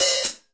MAC_OHH.wav